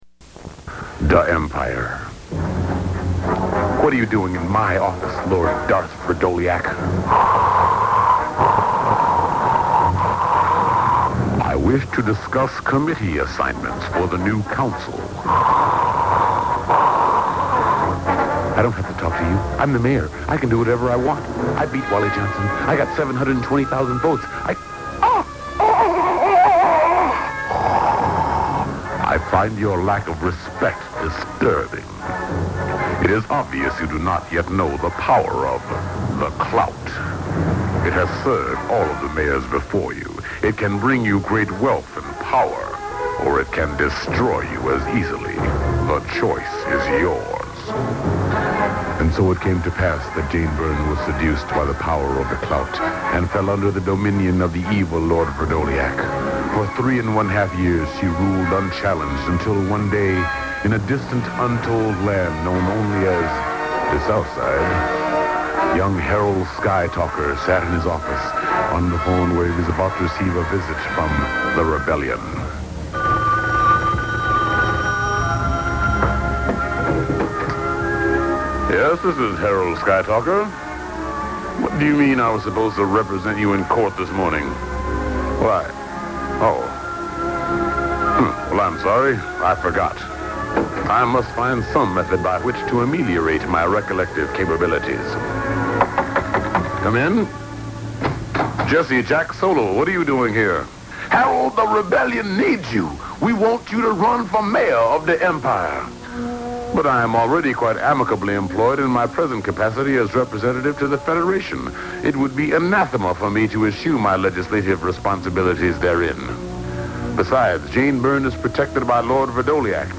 It was a Star Wars themed radio show and comedy play lampooning the Chicago's city "council wars".